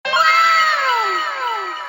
Wow! Sound Effects Views: 1173 Play the iconic Wow! sound button for your meme soundboard!